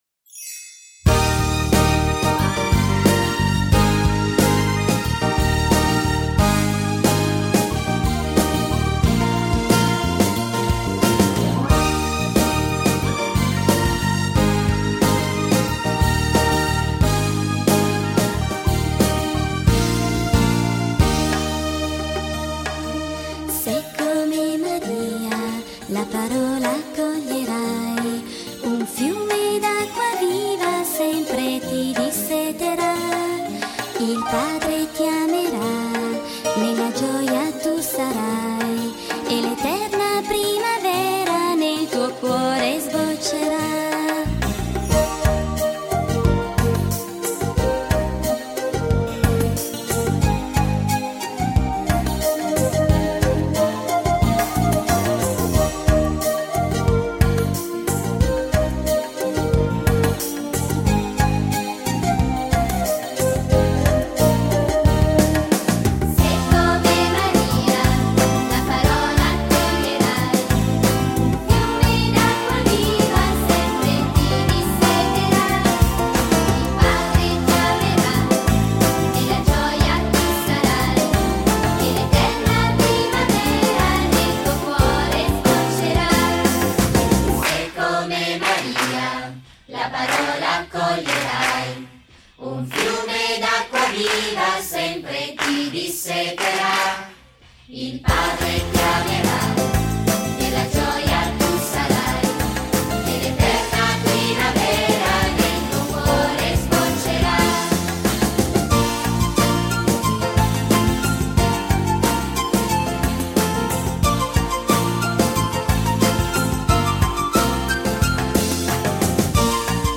Canto per la Decina di Rosario e Parola di Dio